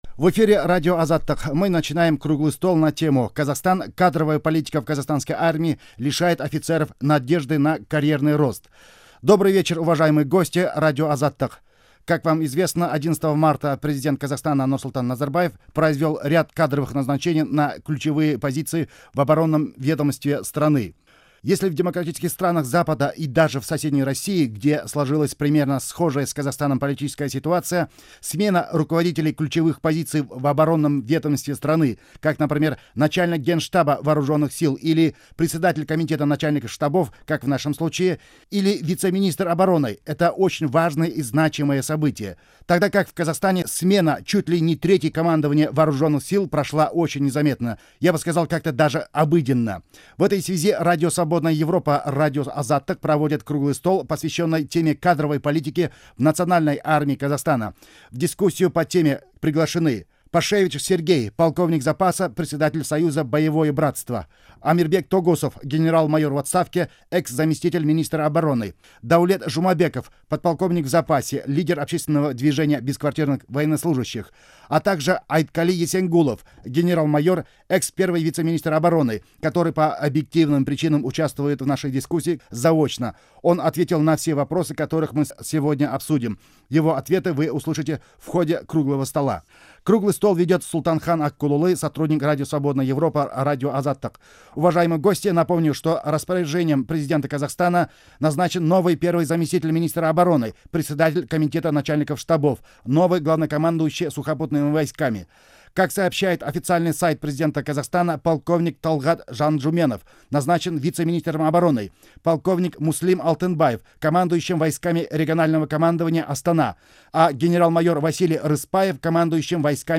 Аудиозапись Круглого стола